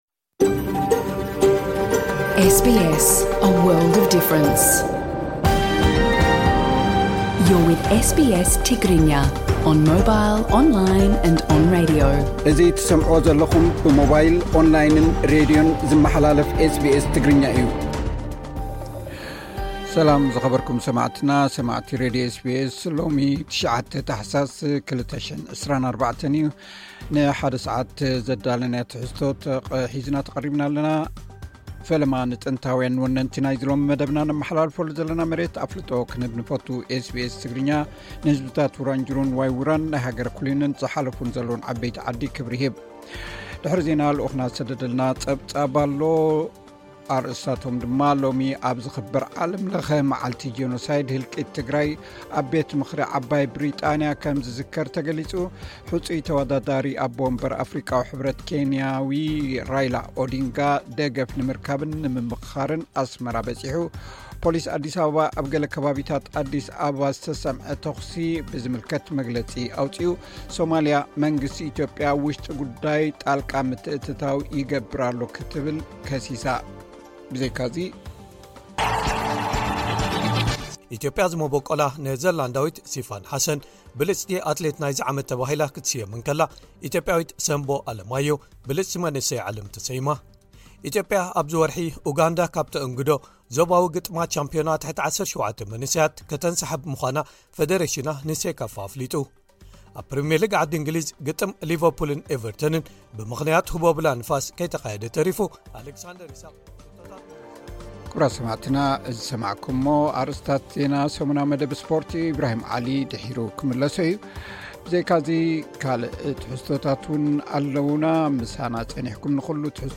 ቀጥታ ምሉእ ትሕዝቶ ኤስ ቢ ኤስ ትግርኛ (9 ጥሪ 2024)